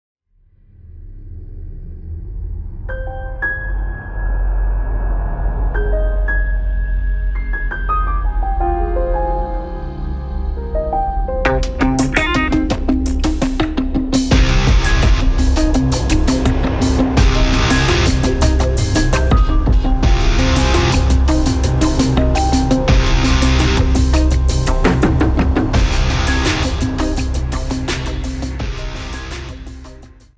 • Type : Instrumental
• Bpm : Andante
• Genre : Metal / semi RIFF